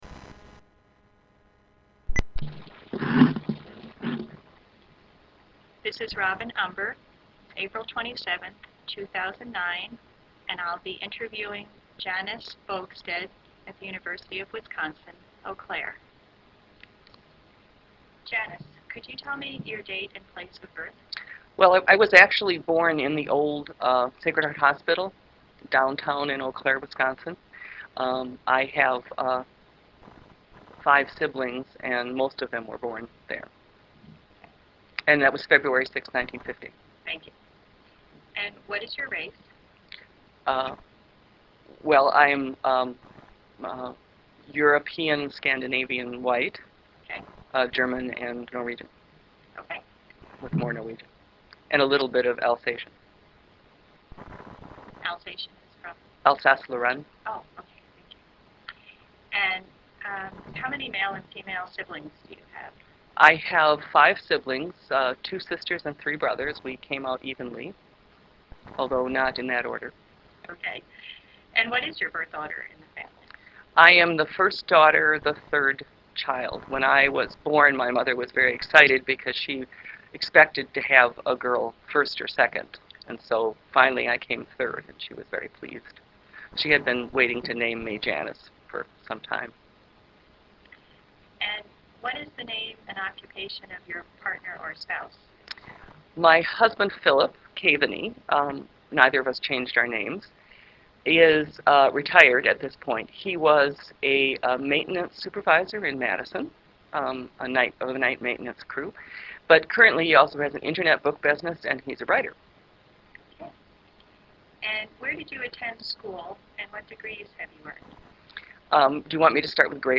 This interview is part of an oral history project conducted in honor of 25th anniversary of the Women's Studies Program at the University of Wisconsin - Eau Claire.